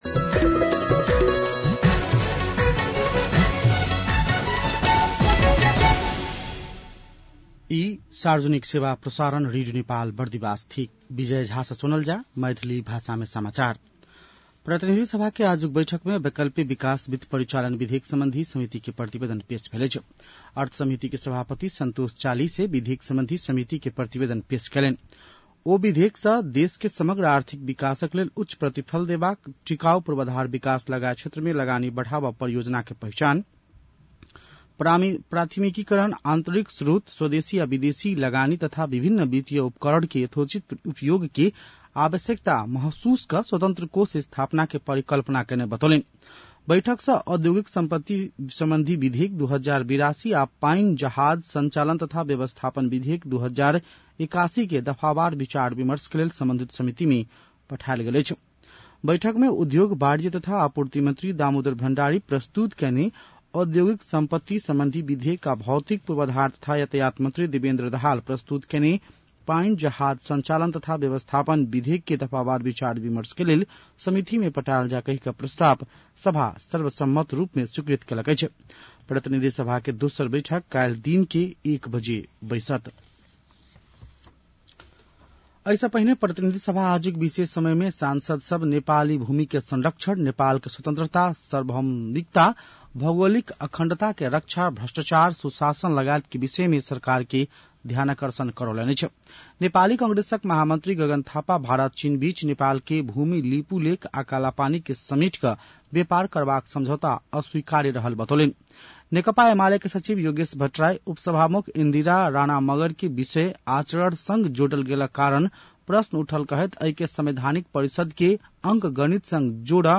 मैथिली भाषामा समाचार : ५ भदौ , २०८२
6-pm-maithali-news-5-5.mp3